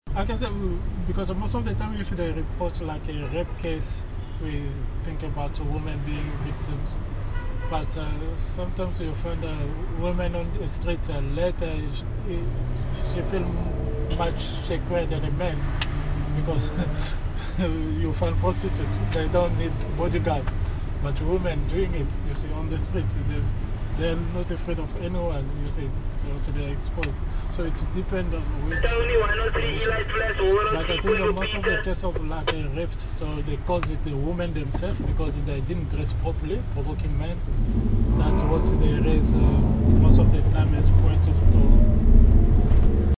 Taxi tales - Cape Town taxi drivers speak about feminism
During their stay, they spoke with taxi drivers about feminism, women in politics and violence against women.